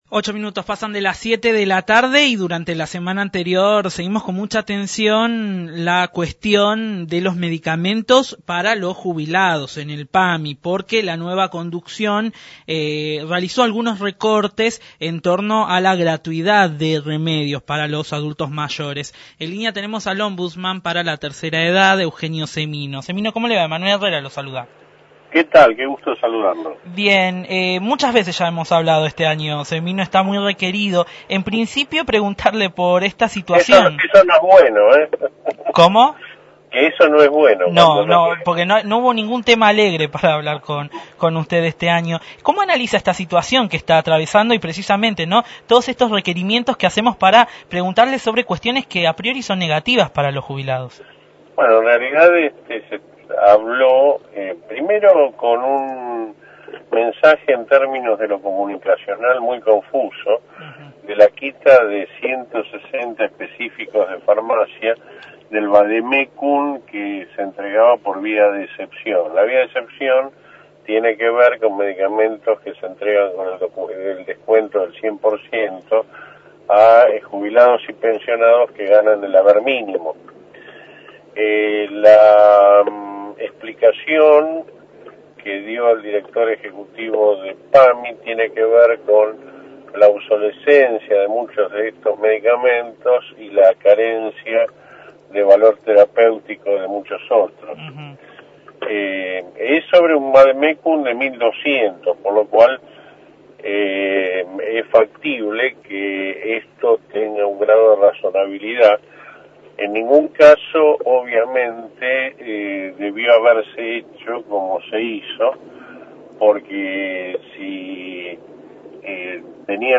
Eugenio Semino, Defensor de la Tercera Edad, hizo hincapié en Nube Sonora a la situación que atraviesan los jubilados por la quita de medicamentos en PAMI y además, brindó detalles de la canasta del adulto mayor.